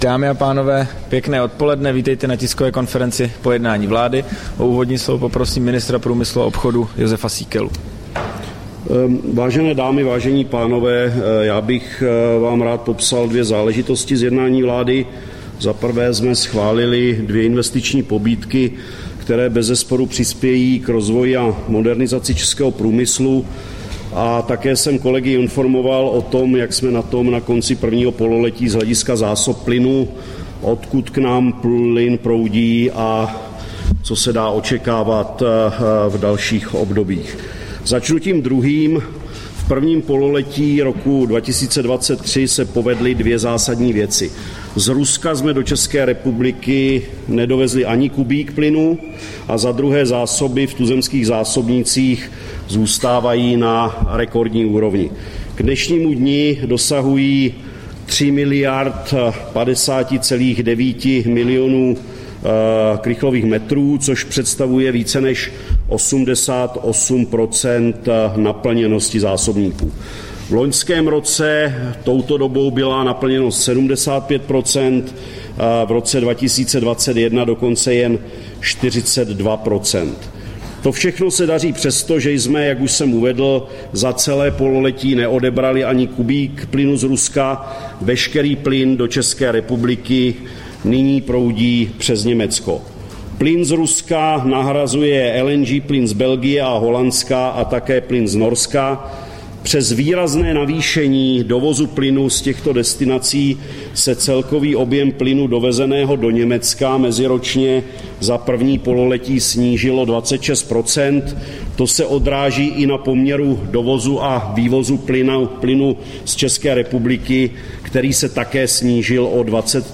Tisková konference po jednání vlády, 19. července 2023